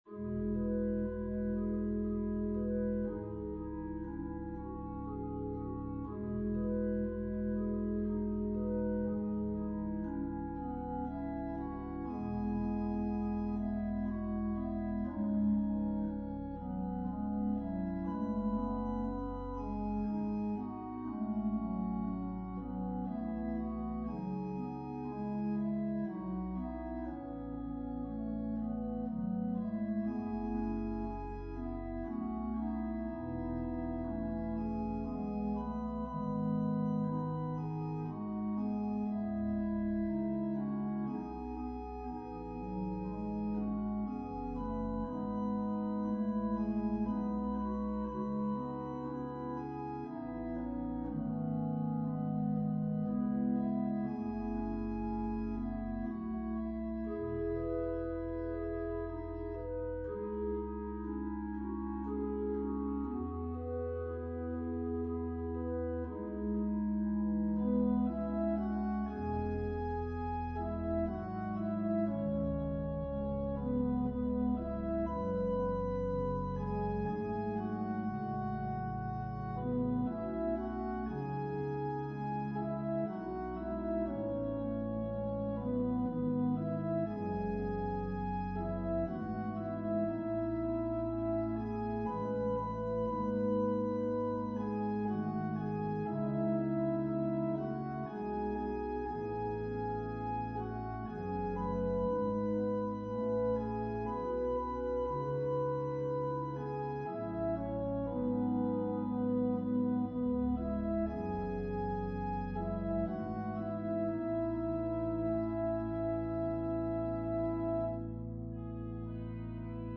Organ/Organ Accompaniment
An organ solo version of Mack Wilberg's choral arrangement of this early American hymn.
An organist who likes to arrange music for organ.